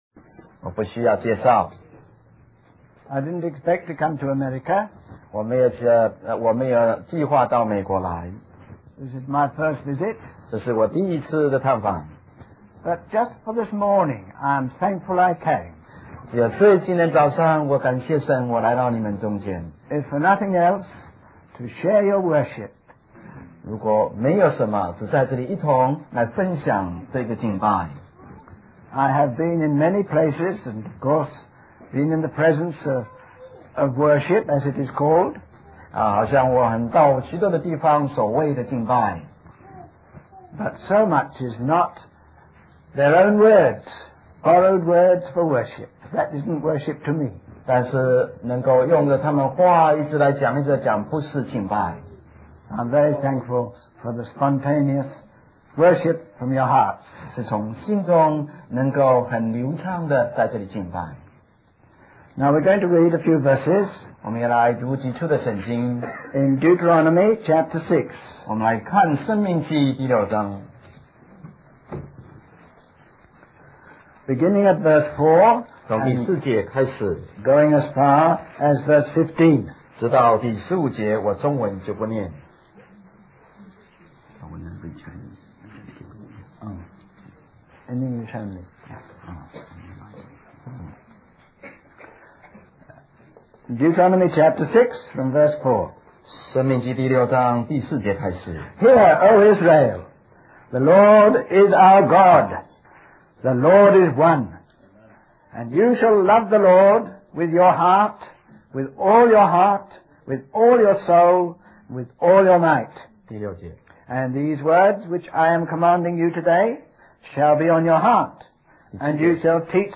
US Stream or download mp3 Summary This message was spoken and English with intermittent Chinese (I think) translation.